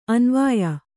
♪ anvāya